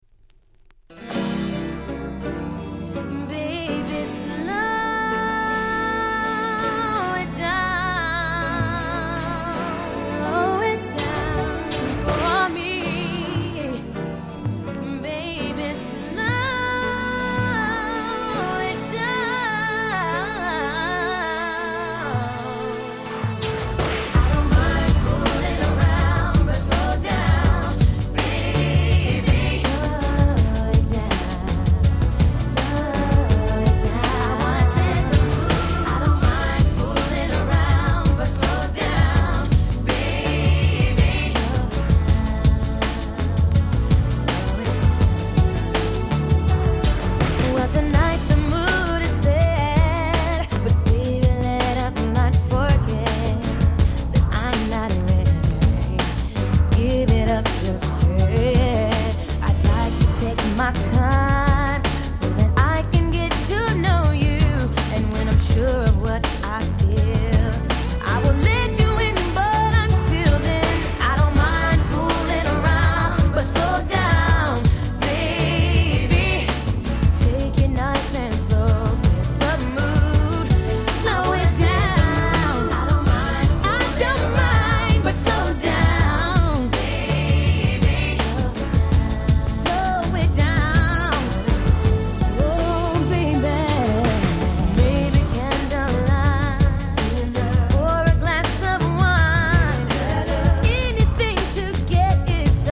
94' Very Nice Slow Jam !!
甘く切ない、そしてエロティックな最高のSlow。